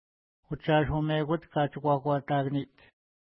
Utshashumekuat katshikakuatakaniht Next name Previous name Image Not Available ID: 399 Longitude: -61.3486 Latitude: 54.3006 Pronunciation: utʃa:ʃumekut ka:tʃəkwa:kwa:ta:kəni:t Translation: Place Where Salmon Were Speared Feature: locality